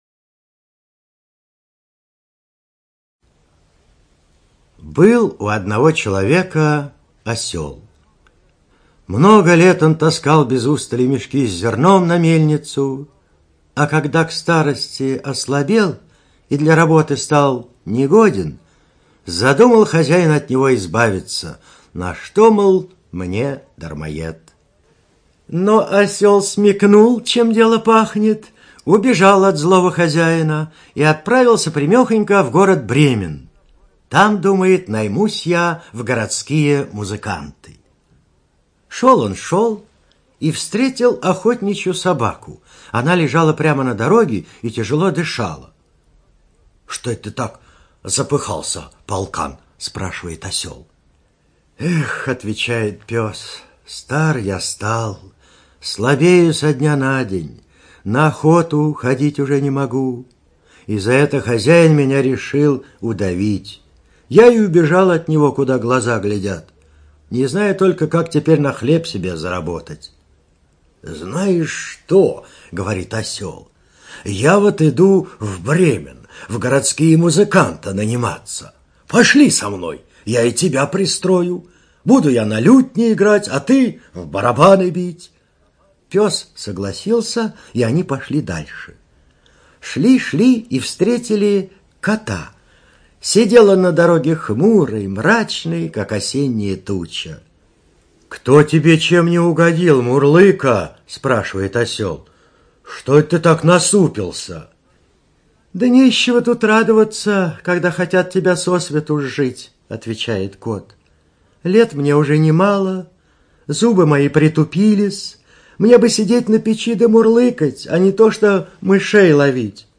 ЧитаетТолмазов Б.